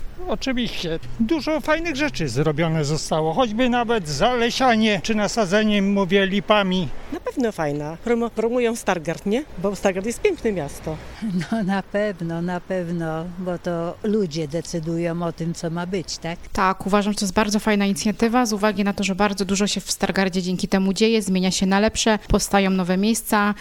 Zapytaliśmy stargardzian, czy uważają, że budżet obywatelski to dobra inicjatywa.